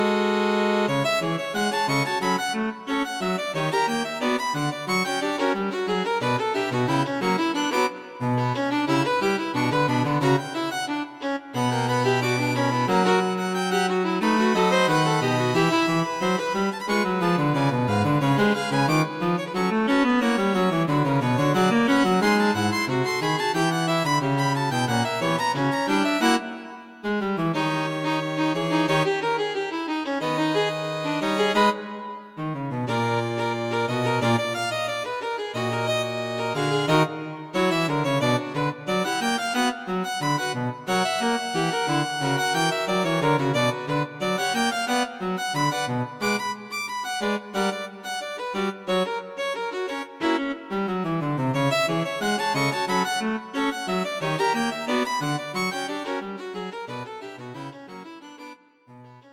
String Quartet for Concert performance